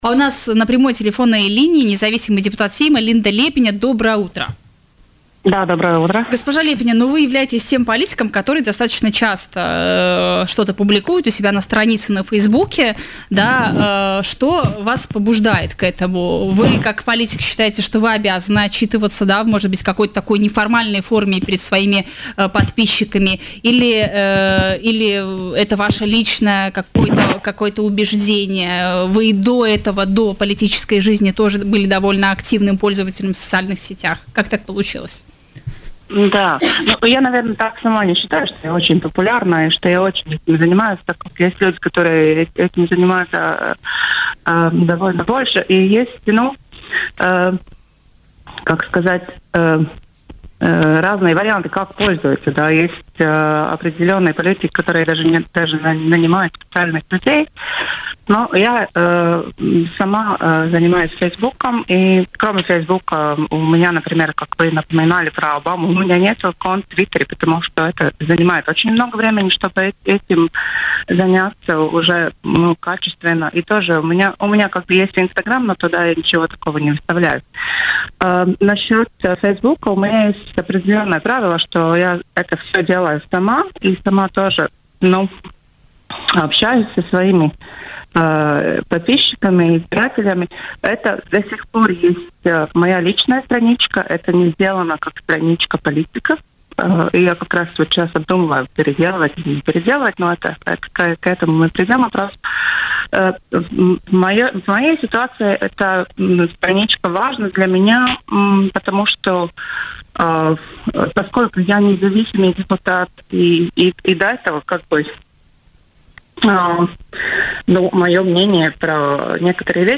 Социальные сети являются важной платформой для политика, в том числе в Латвии. Такое мнение в эфире радио Baltkom высказала независимый депутат Сейма, ранее покинувшая партию KPV LV Линда Лиепиня.